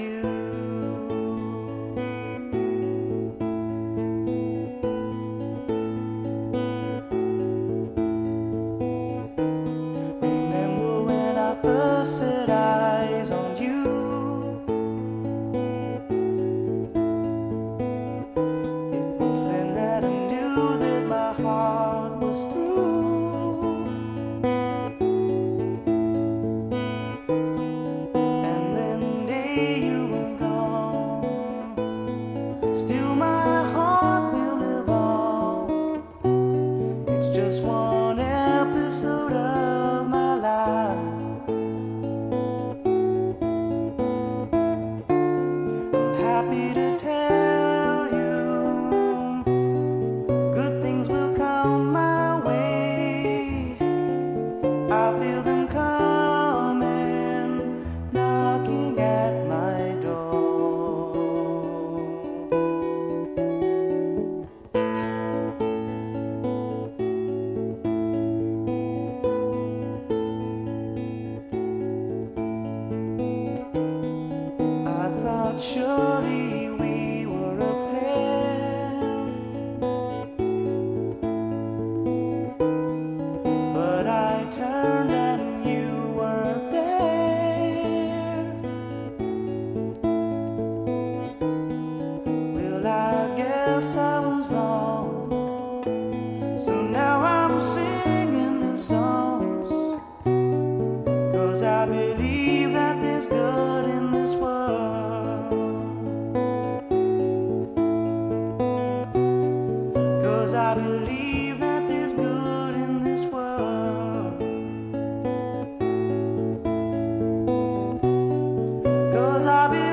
美しいメロディー、ハーモニーに思わず聴き入ってしまう傑作揃いです！